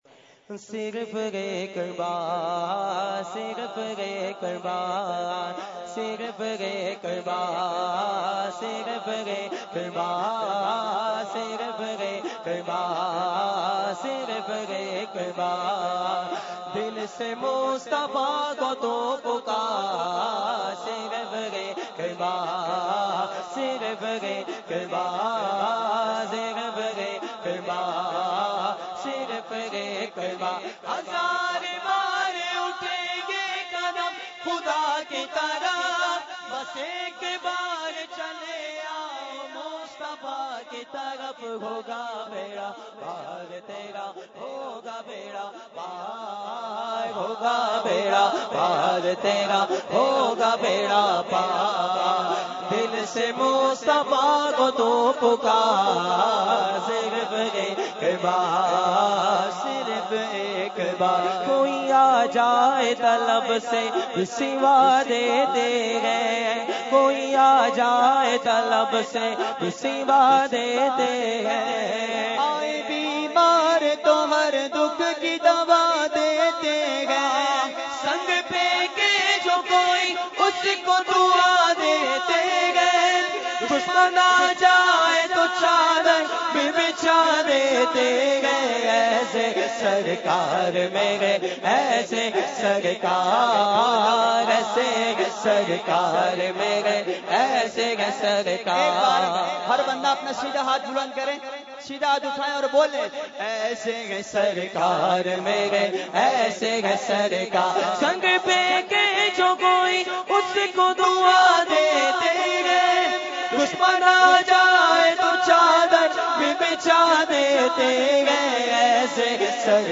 Category : Naat | Language : UrduEvent : Urs Makhdoome Samnani 2017